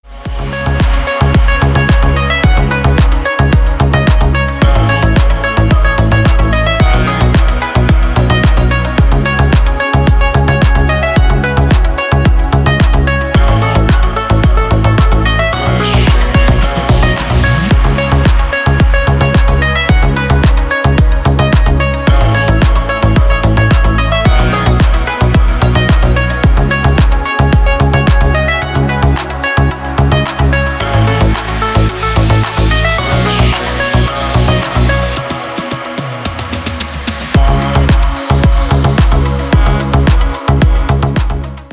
Дип Хаус нарезка для звонка